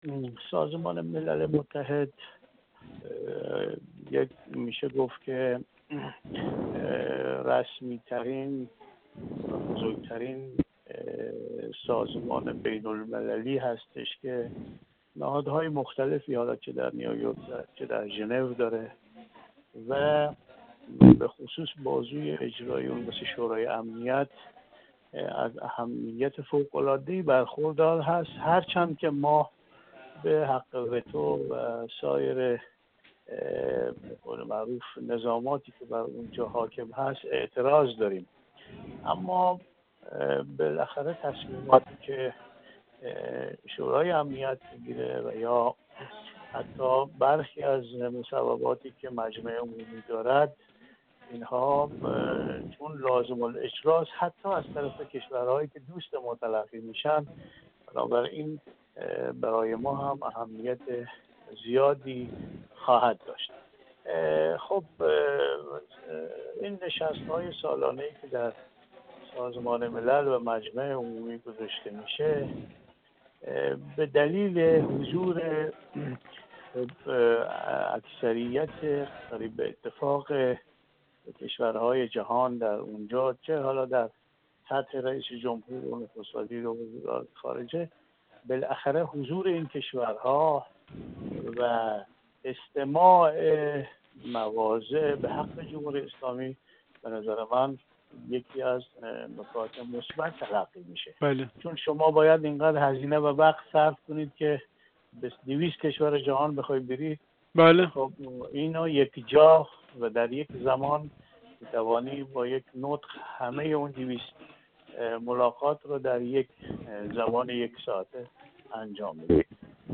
کارشناس مسائل سیاسی با بیان اینکه آمریکا تا قبل از انتخابات کنگره آمادگی مذاکره واقعی و به سرانجام رساندن برجام را ندارد، گفت: این مسئله فرصت خوبی برای رئیس‌جمهور است که در مجمع عمومی سازمان ملل مسیر طی شده در برجام از سوی ایران و طرف‌های مقابل را تشریح کرده و دلایل پافشاری ایران بر گرفتن یک تضمین محکم از آمریکا برای عدم خروج مجدد از برجام را تبیین کند.